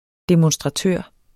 Udtale [ demɔnsdʁɑˈtøˀʁ ]